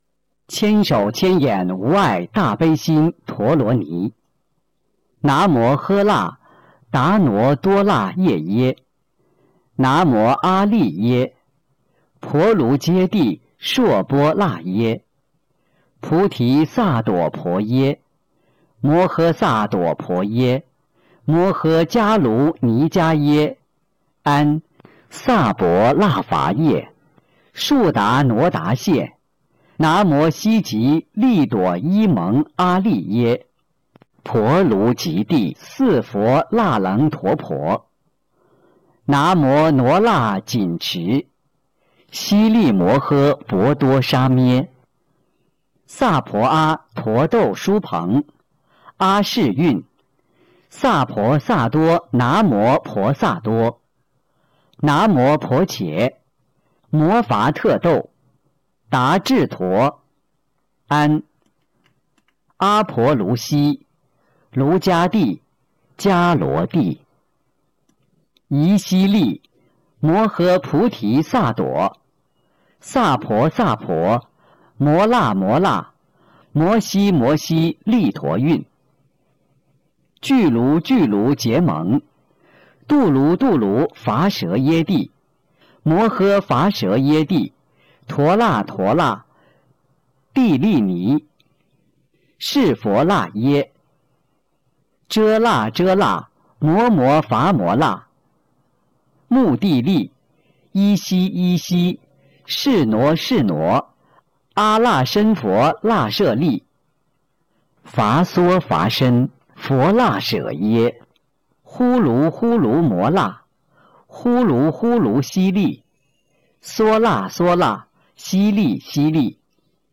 002《大悲咒》教念男声